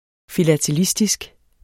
Udtale [ filatəˈlisdisg ]